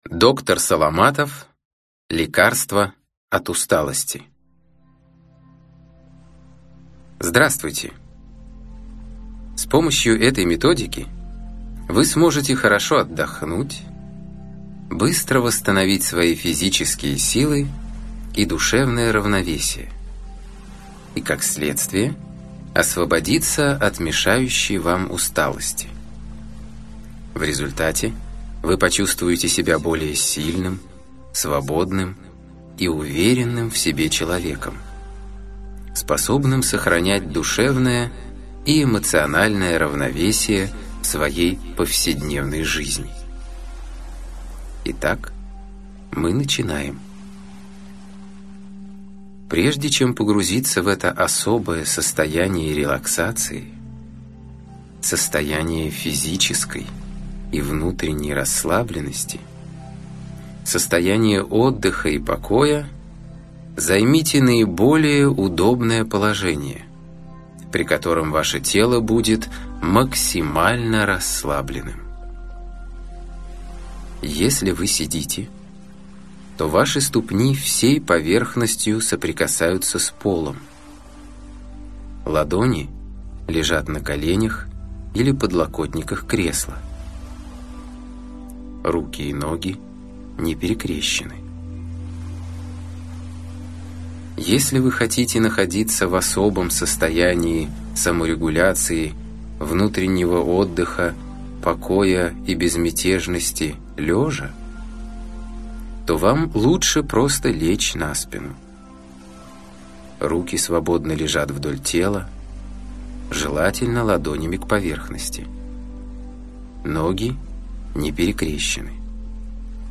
Аудиокнига Лекарство от усталости | Библиотека аудиокниг